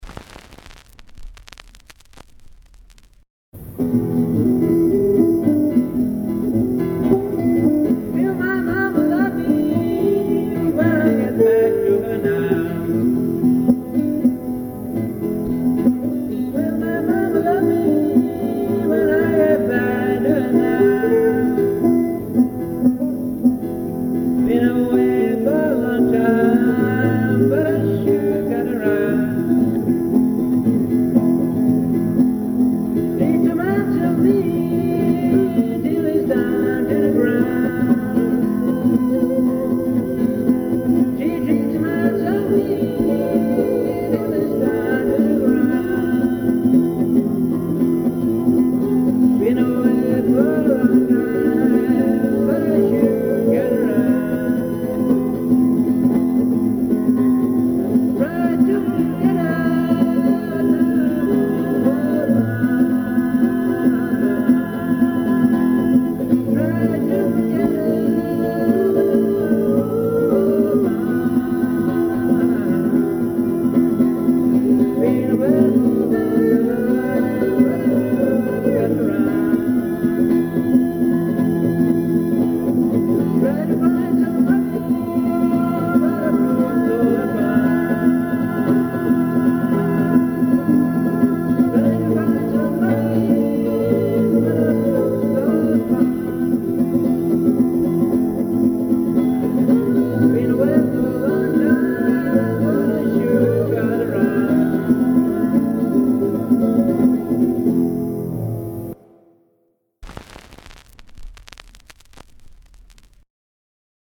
Live recording of an unrehearsed impromptu performance
guitar and vocals
Unplugged, of course.